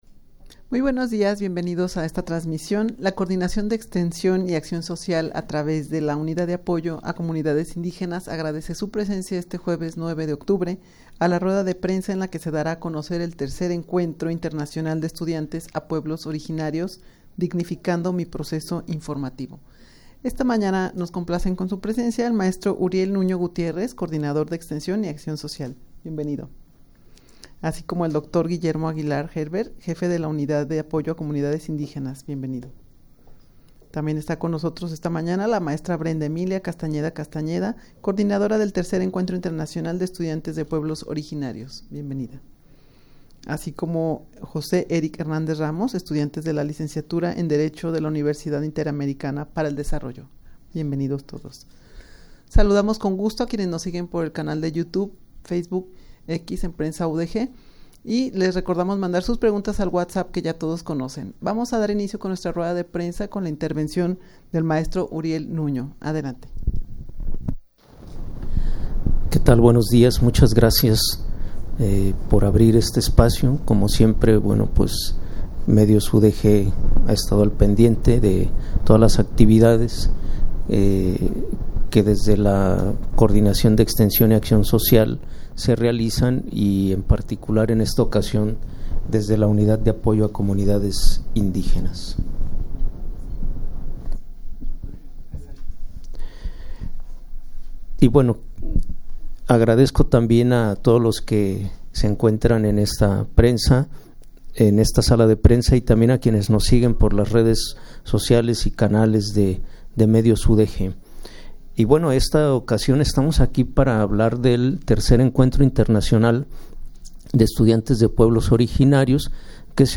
Audio de la Rueda de Prensa
rueda-de-prensa-en-la-que-se-dara-a-conocer-el-tercer-encuentro-internacional-de-estudiantes-de-pueblos-originarios.mp3